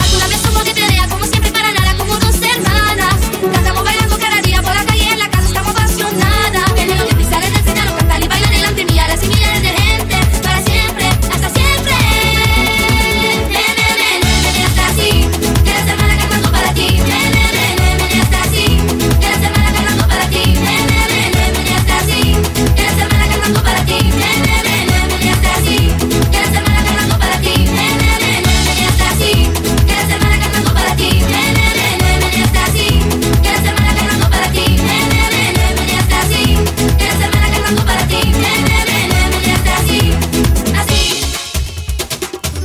Genere: latin pop, latin house, latin tribal, bachata